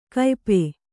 ♪ kaype